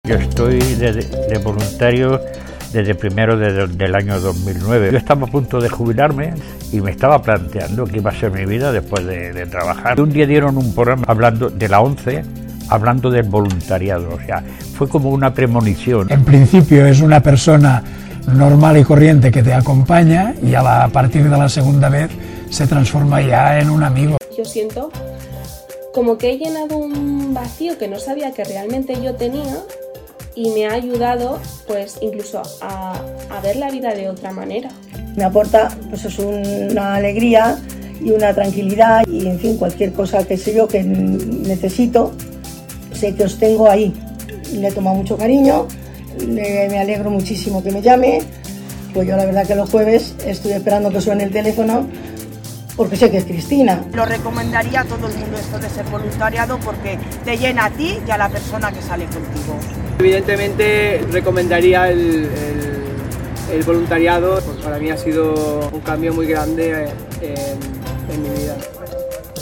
De Castilla-La Mancha, Cataluña, Valencia... Los mensajes tanto de los voluntarios y voluntarias y de los usuarios afiliados y afiliadas de este servicio, resumen esta acción solidaria en la idea de un "beneficio mutuo" y